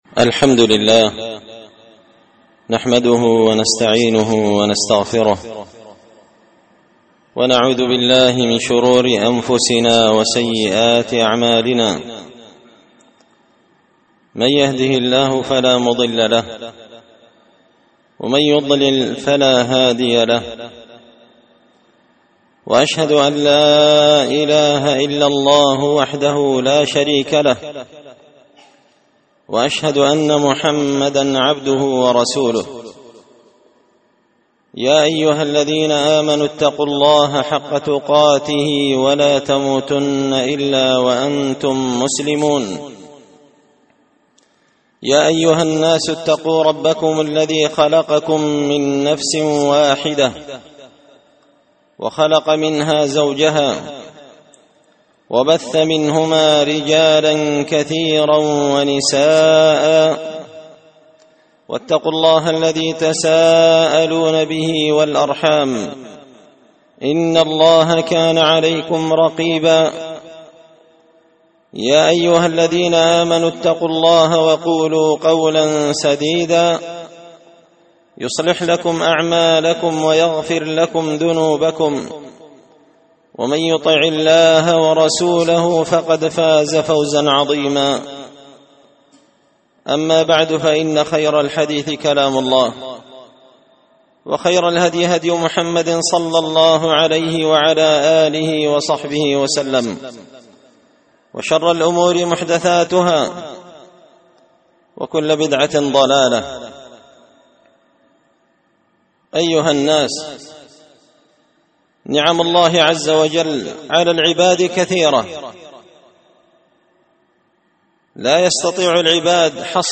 خطبة جمعة بعنوان – البيان الماتع لكيفية الاستسقاء في الجمعة وتوضيح
دار الحديث بمسجد الفرقان ـ قشن ـ المهرة ـ اليمن